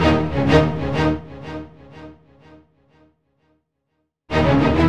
Hands Up - Egyptian Layer.wav